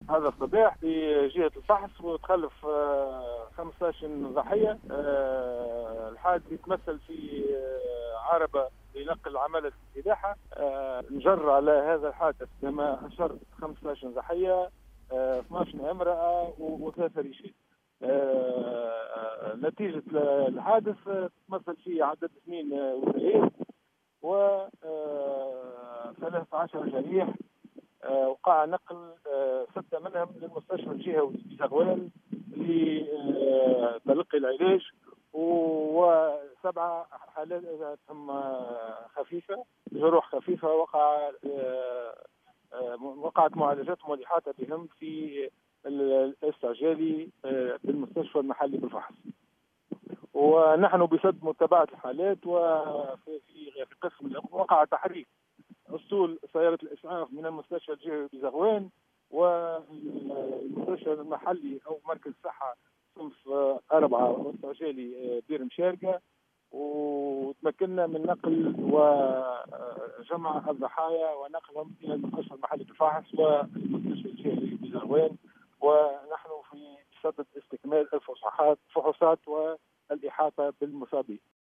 أوضح المدير الجهوي للصحة بزغوان عبد الكريم عمر في تصريح للجوهرة اف ام أن حادث المرور الذي جدّ صباح اليوم بالفحص، أسفر عن وفاة شخصين وإصابة 13 آخرين.